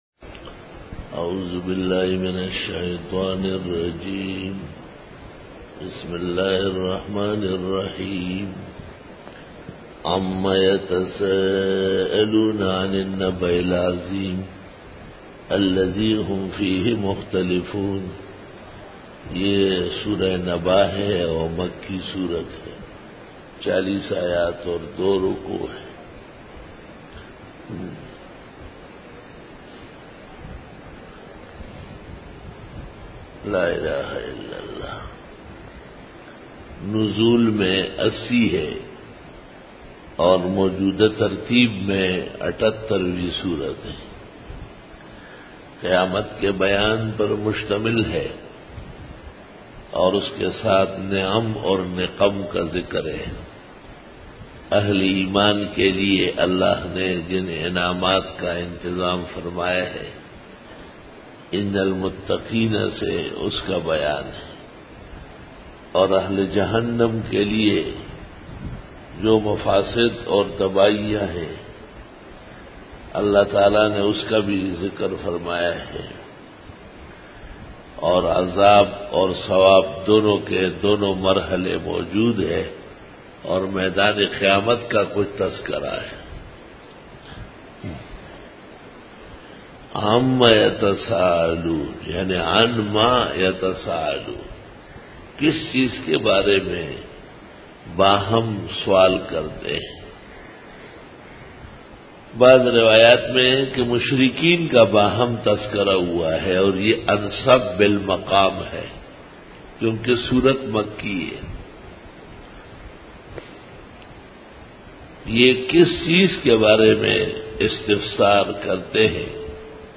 Dora-e-Tafseer 2012